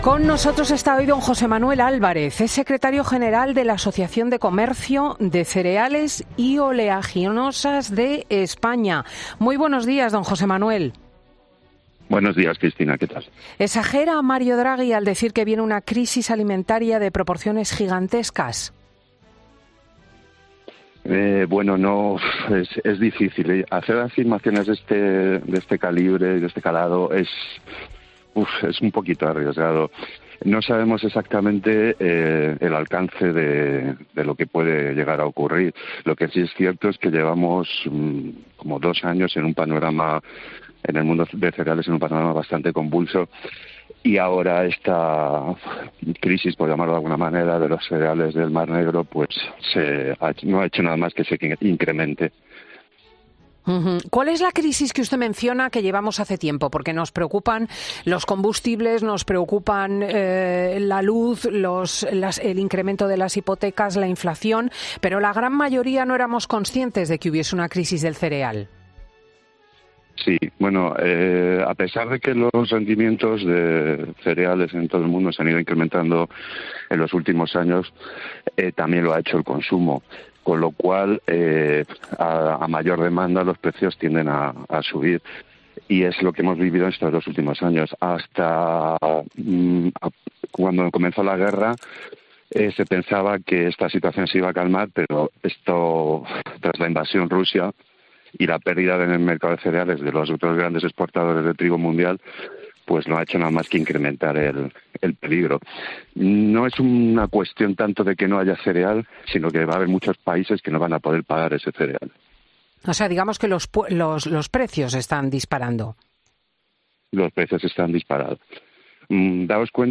El sector del cereal analiza en COPE el aviso de Mario Draghi sobre el futuro de de las exportaciones agrícolas tras la invasión de Ucrania